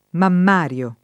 vai all'elenco alfabetico delle voci ingrandisci il carattere 100% rimpicciolisci il carattere stampa invia tramite posta elettronica codividi su Facebook mammario [ mamm # r L o ] agg.; pl. m. -ri (raro, alla lat., ‑rii )